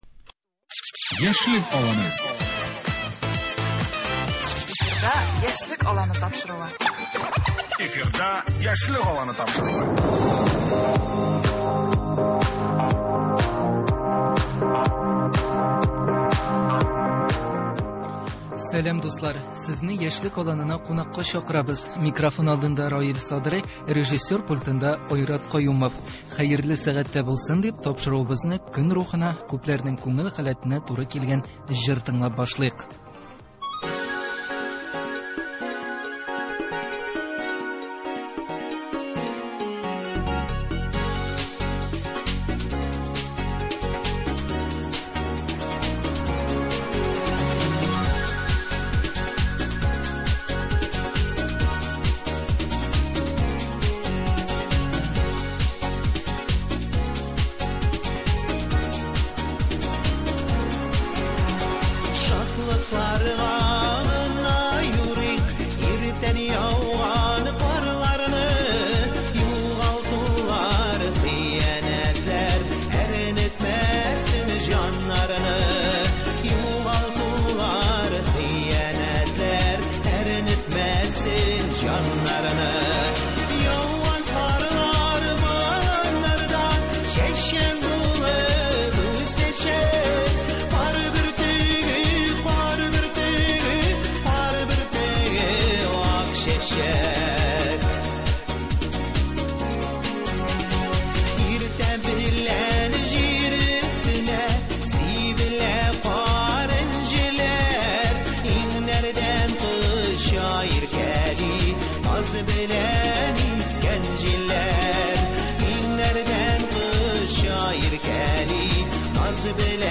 Шундый мизгелдә шигырь тыңлыйсы килә. Шуны истә тотып бүген без студиябезгә яшь иҗатчы, шагыйрә